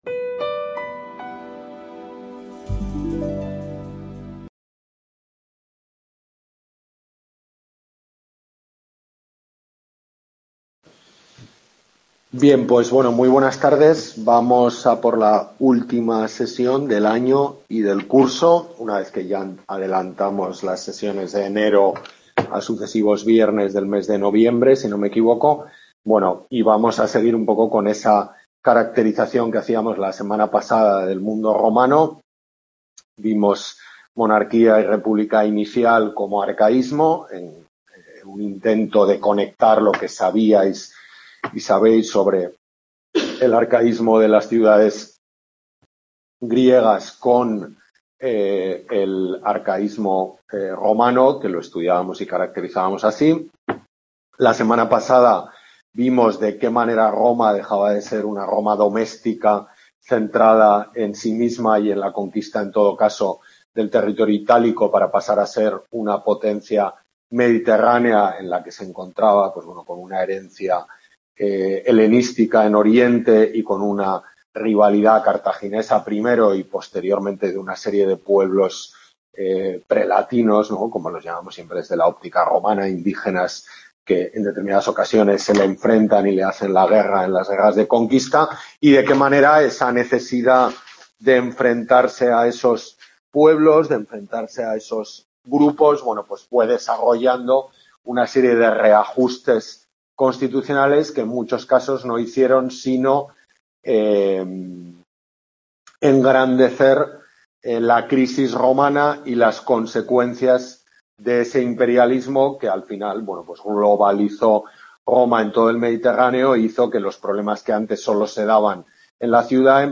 Tutoría de Historia Antigua del Grado de Arte en la UNED de Pamplona